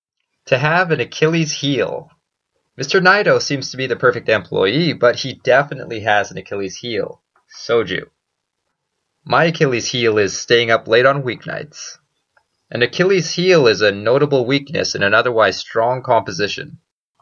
英語ネイティブによる発音は下記のリンクをクリックしてください 。